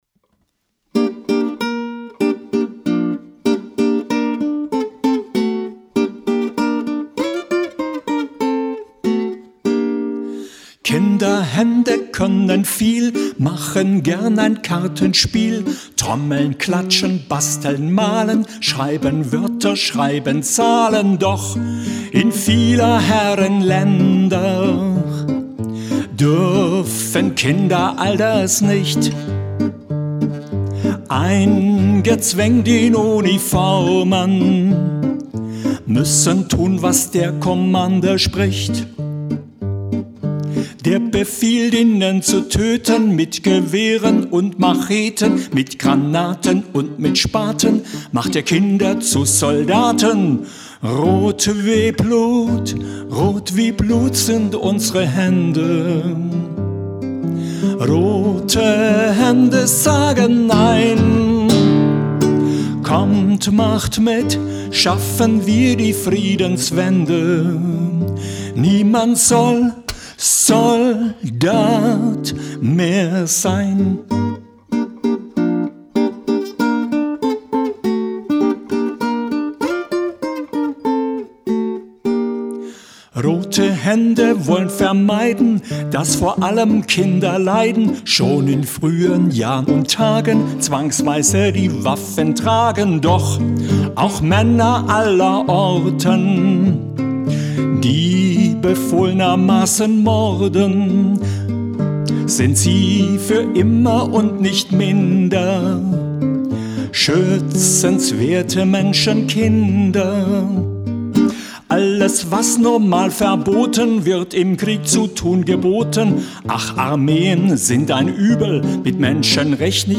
The songs were written and recorded under a tight time schedule, so these are just workshop recordings.